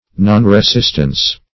nonresistance - definition of nonresistance - synonyms, pronunciation, spelling from Free Dictionary
Nonresistance \Non`re*sist"ance\, n.